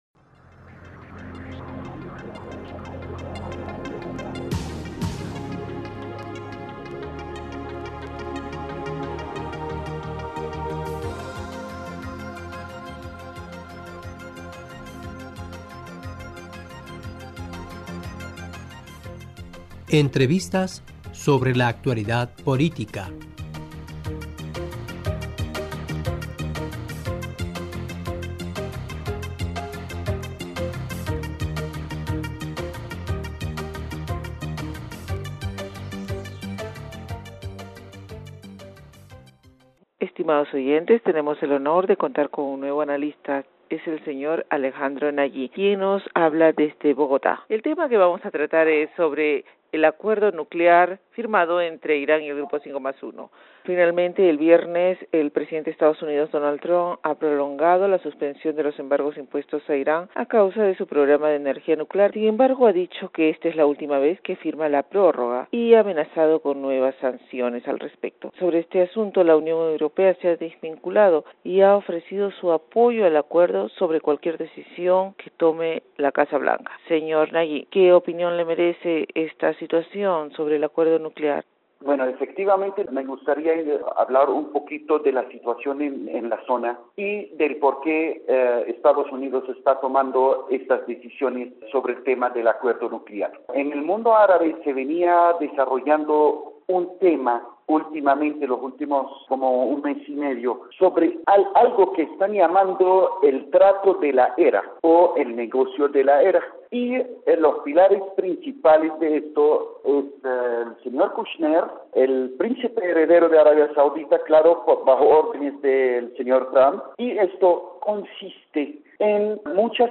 E: Estimados oyentes tenemos el honor de contar con un nuevo analista